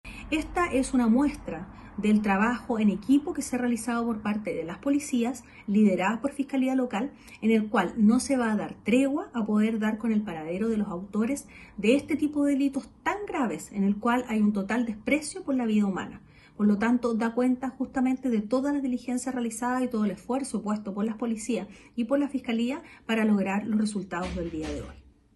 La Fiscal Jefe de Osorno, María Angélica de Miguel señaló que desde el primer día la Brigada de Homicidios comenzó con las diligencias para dar con el paradero de los involucrados, quienes fueron formalizados por el delito de homicidio, agresiones y disparos injustificados.